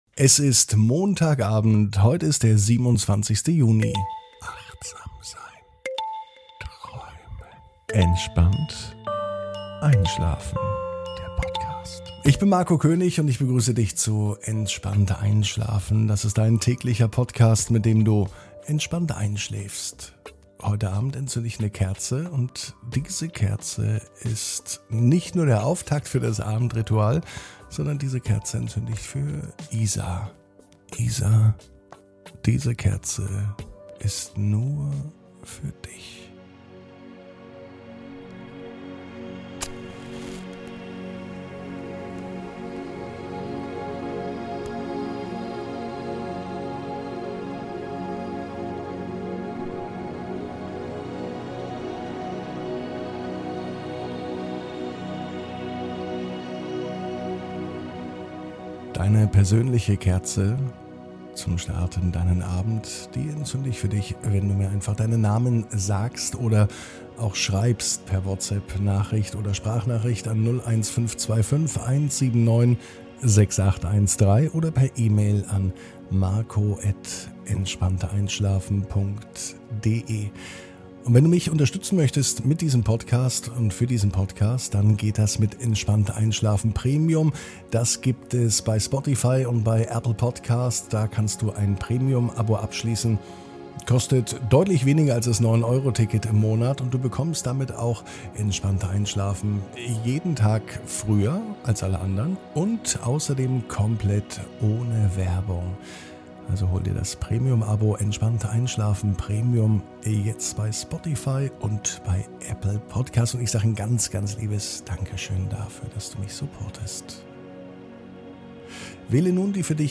(ohne Musik) Entspannt einschlafen am Montag, 27.06.22 ~ Entspannt einschlafen - Meditation & Achtsamkeit für die Nacht Podcast